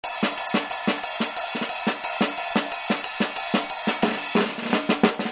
Caisse.mp3